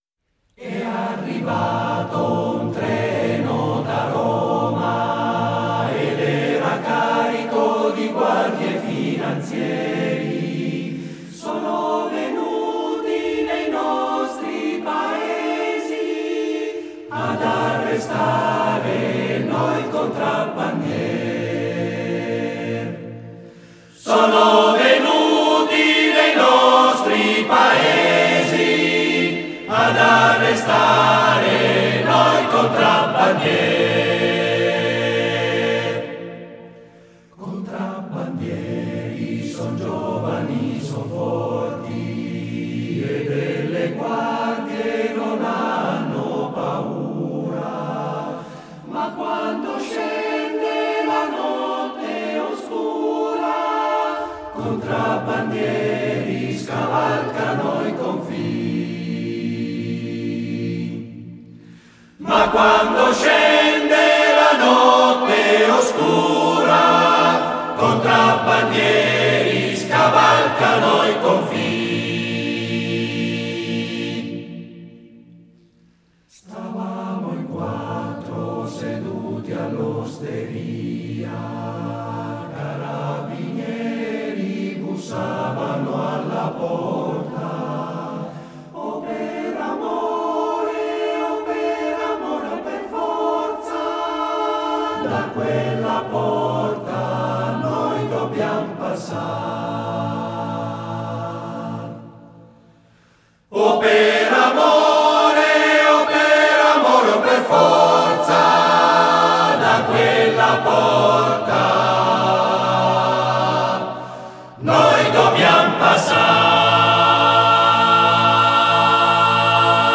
Arrangiatore: Mazzari, Gino
Esecutore: Coro CAI Uget